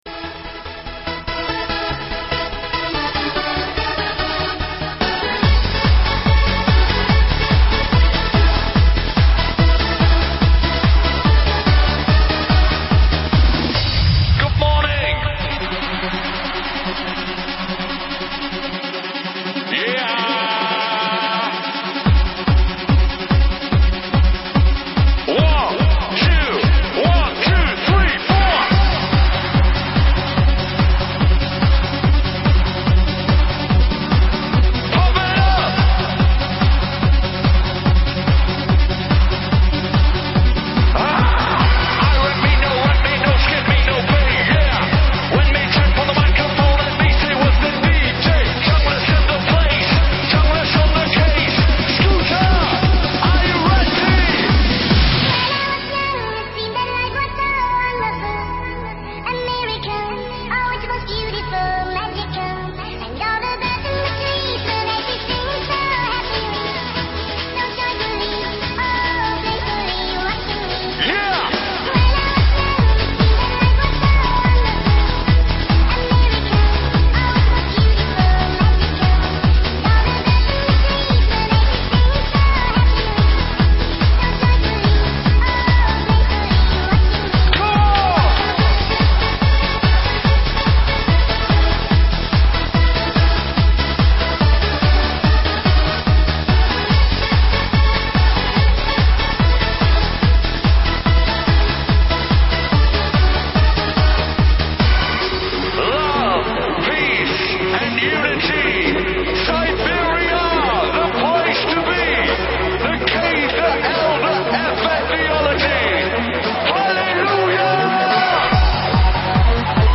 [9/10/2008]迪斯科舞曲